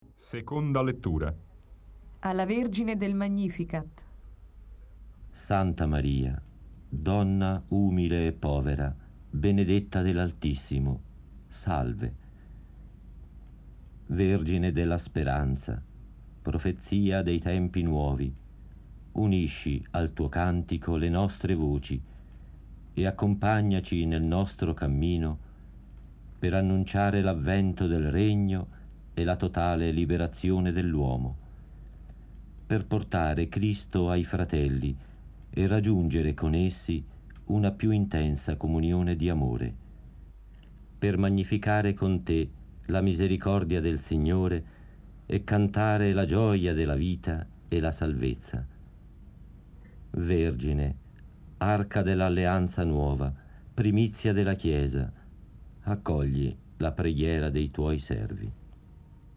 Seconda lettura 1,392 Mb   Ascolto